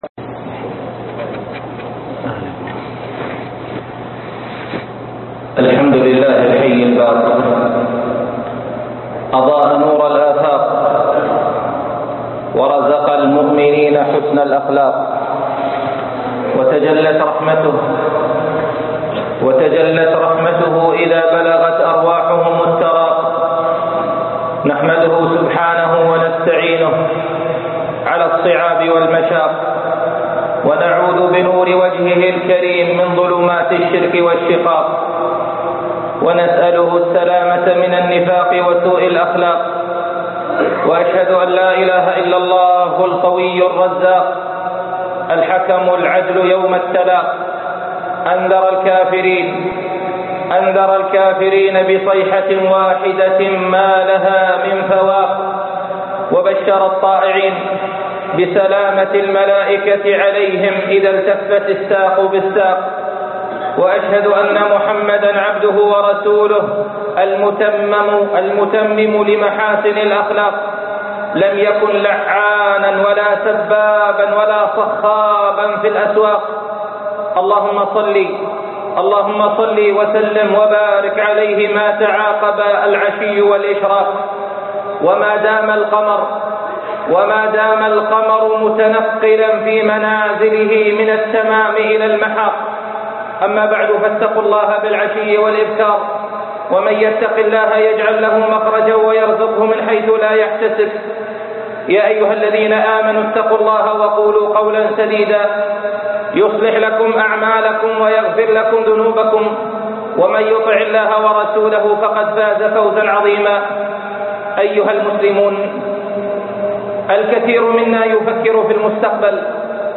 صناعه الشرف - خطب الجمعه